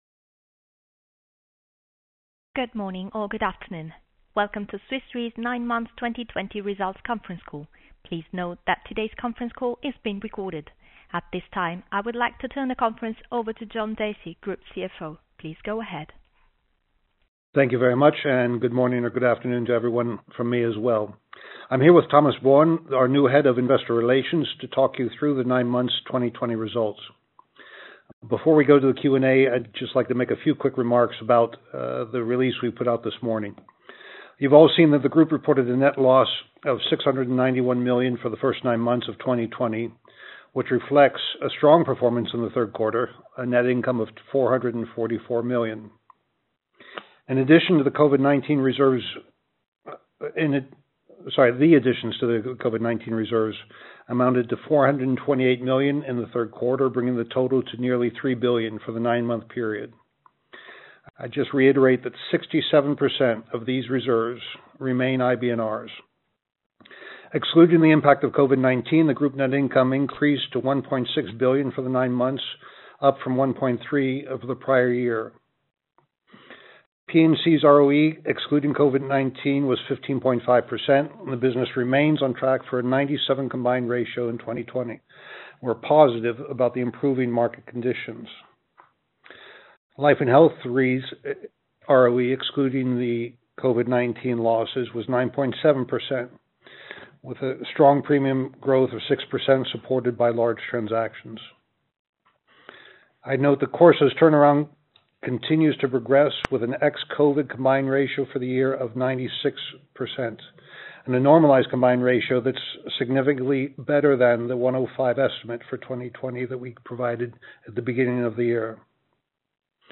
9m-2020-call-recording.mp3